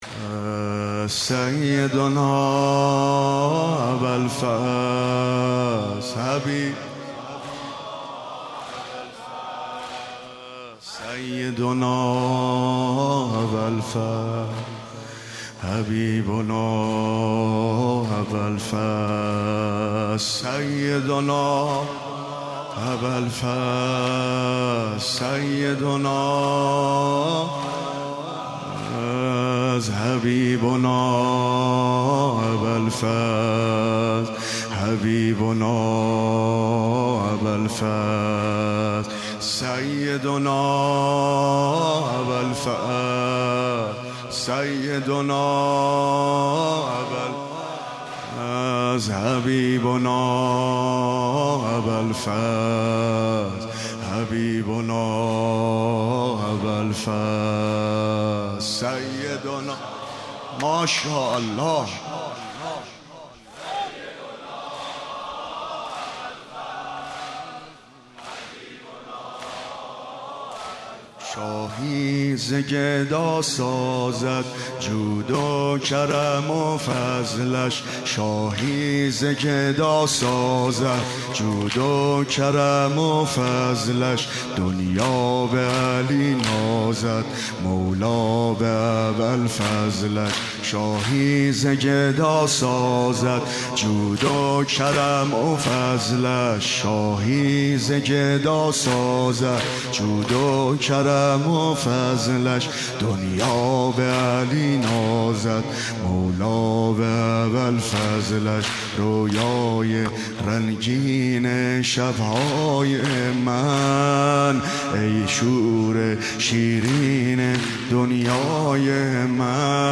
مداحی عبدالرضا هلالی در شب تاسوعا را بشنوید.